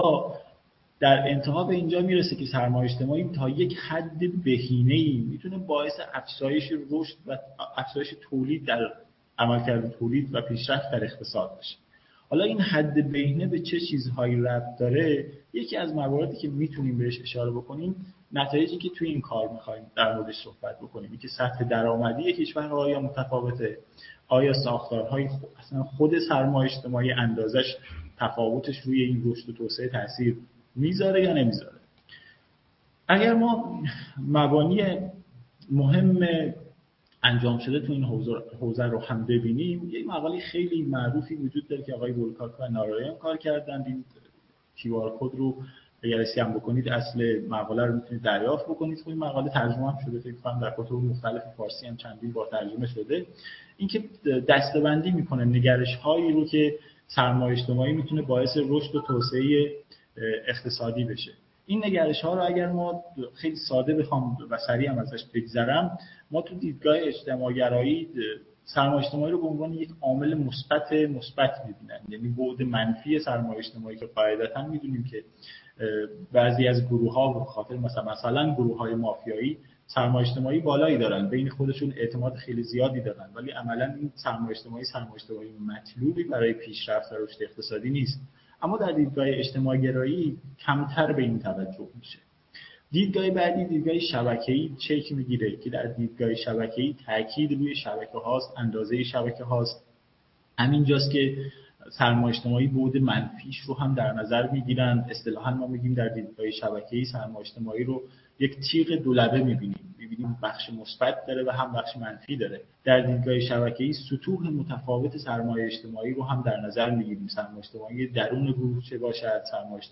نشست چهارم: بحث و گفتگو در چال شهای رشد اقتصادی ایران